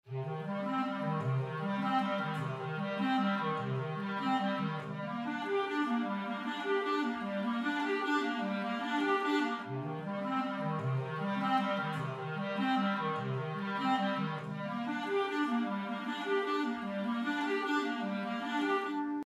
Solche Clarinetten-Arpeggien erinnern an Minimal Music à la Philip Glass.